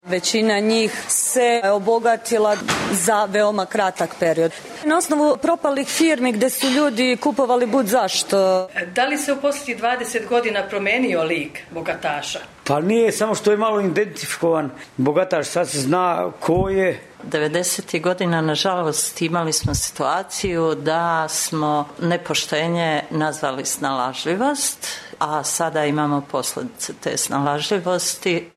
Stavovi građana: